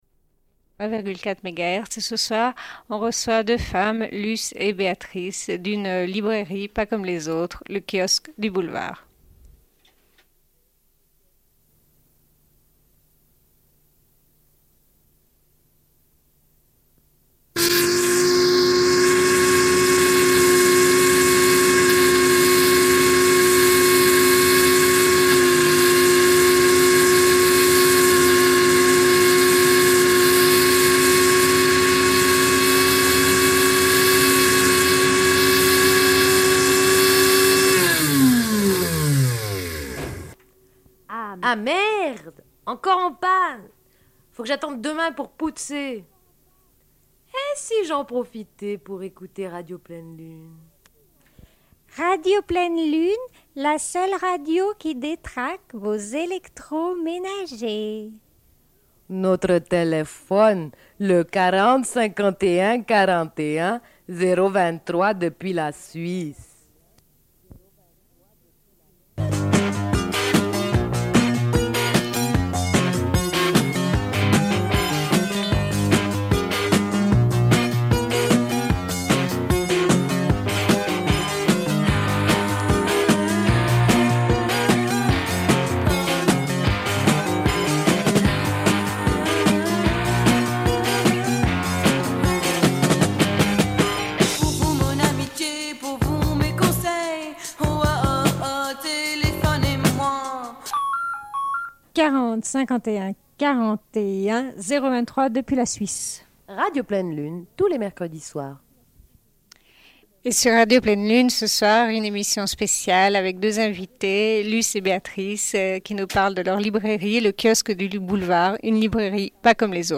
Une cassette audio, face A31:15